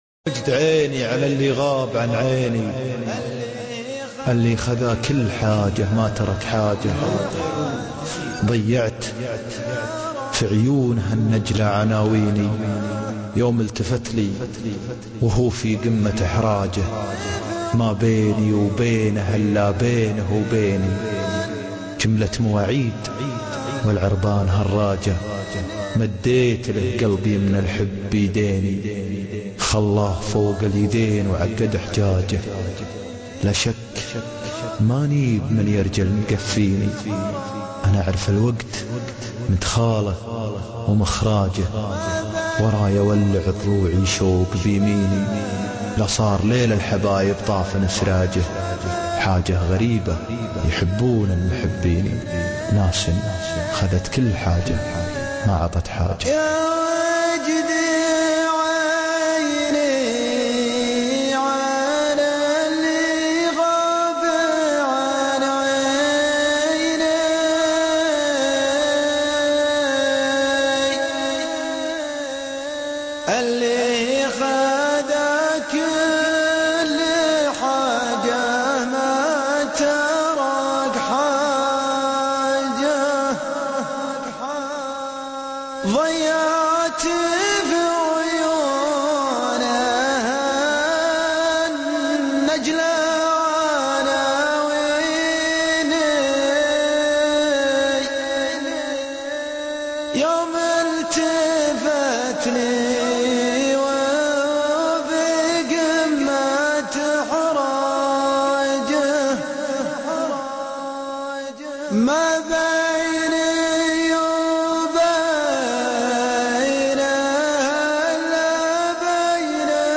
القاء+شيله